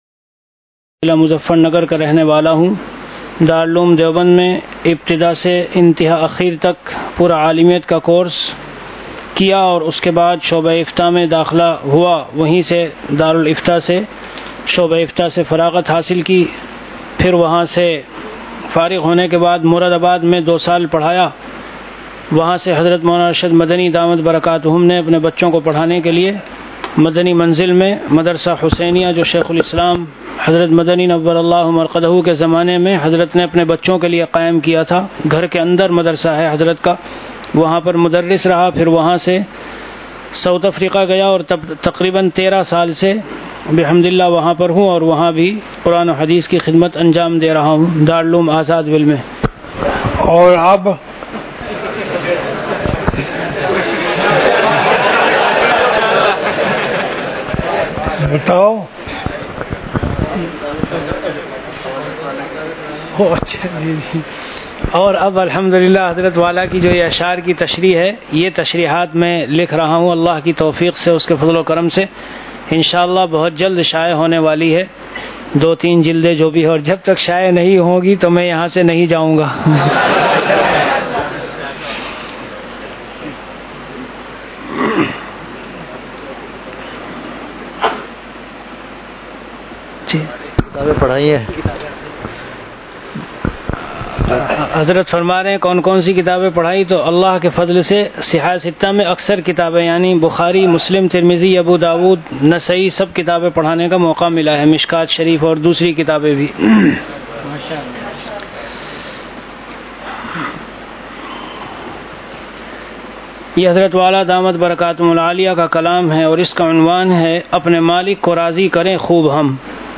Delivered at Khanqah Imdadia Ashrafia.
Bayanat · Khanqah Imdadia Ashrafia